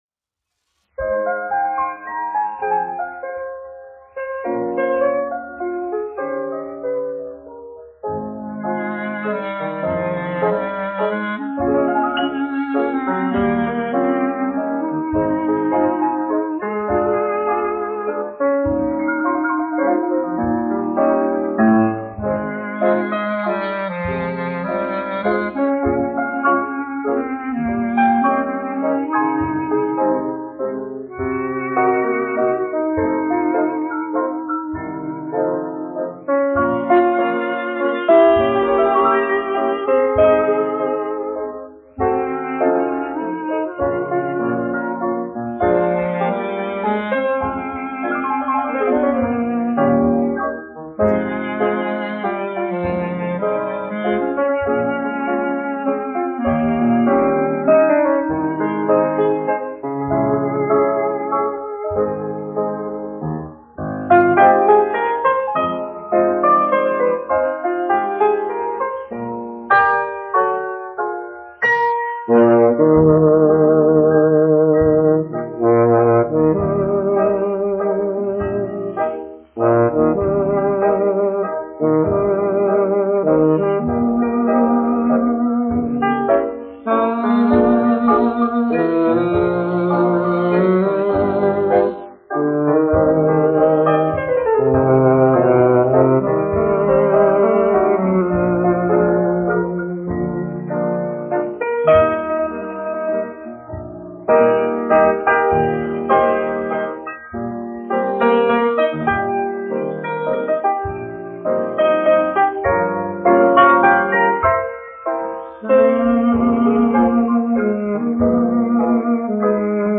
1 skpl. : analogs, 78 apgr/min, mono ; 25 cm
Populārā instrumentālā mūzika